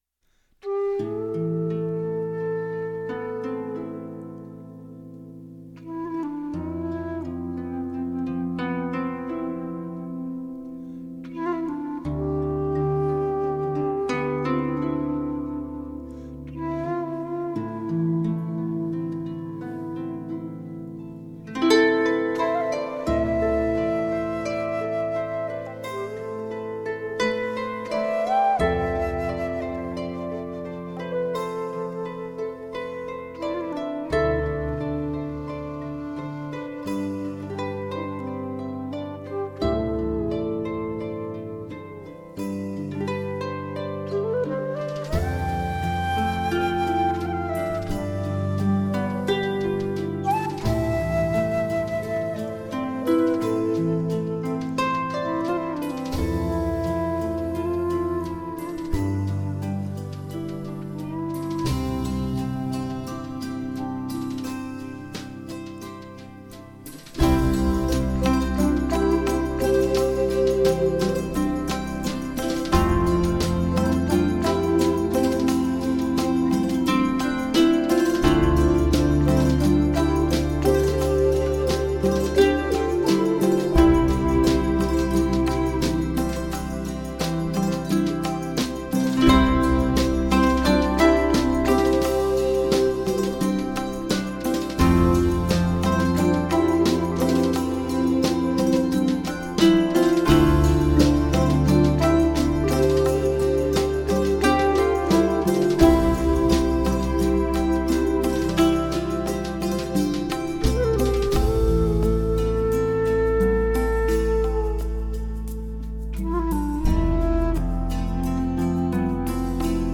她的音乐较之一般的凯尔特更有一种浓浓的暖意，犹如照亮圣诞夜的烛火，点燃人们心里的甜蜜。